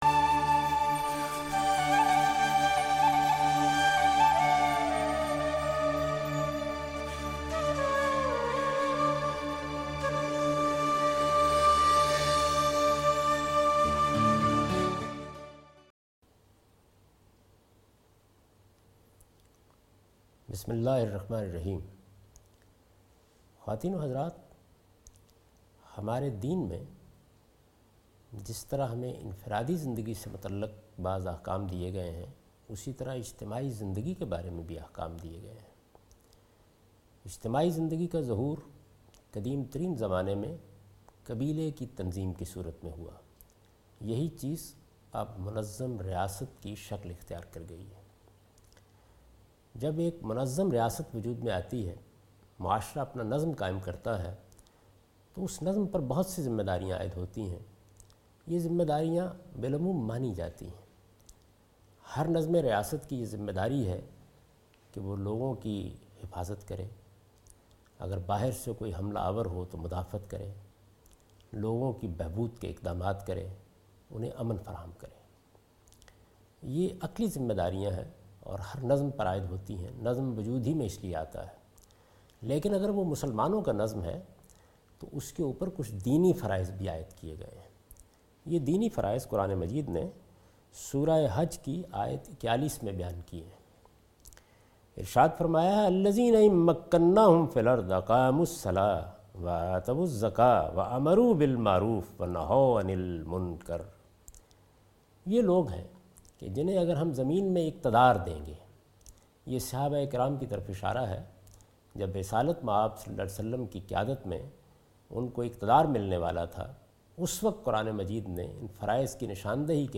This series contains the lecture of Javed Ahmed Ghamidi delivered in Ramzan.